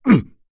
Bernheim-Vox_Landing.wav